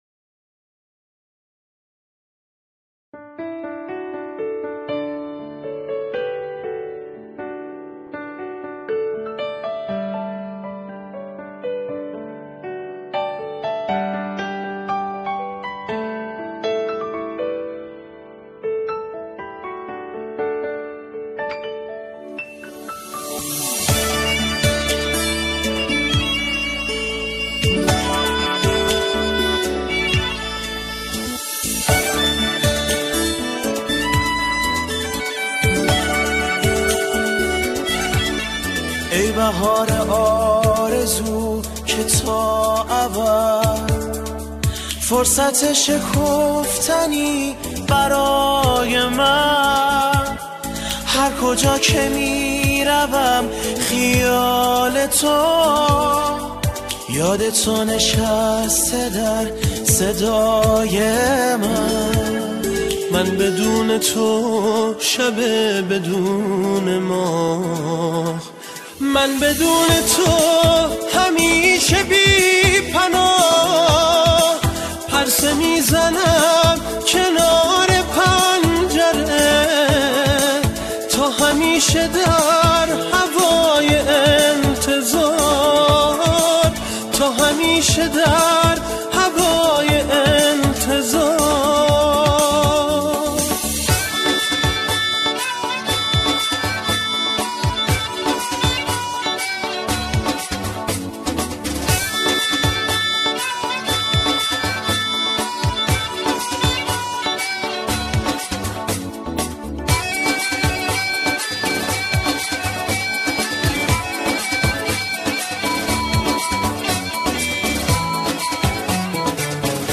سرودهای امام زمان (عج)